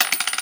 drop2.mp3